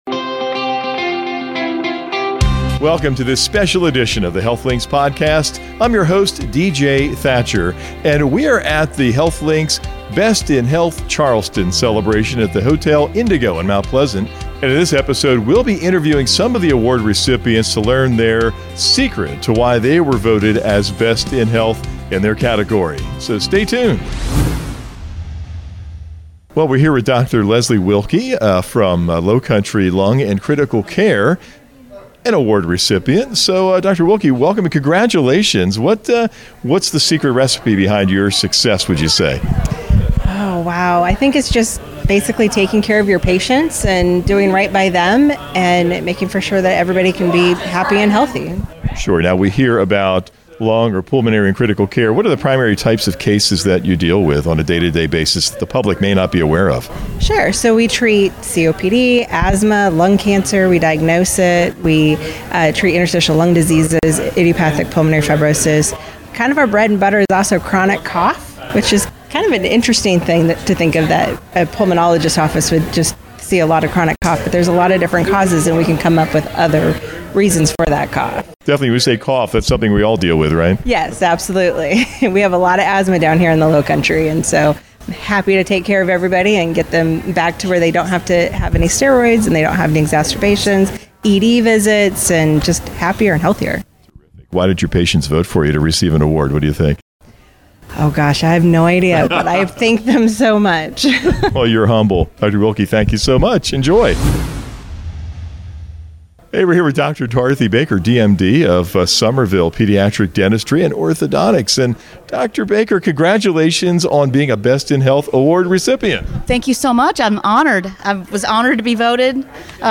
Interviews with 2024 Best in Health Winners - HealthLinks SC